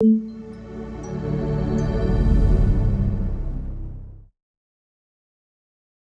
pda_welcome.ogg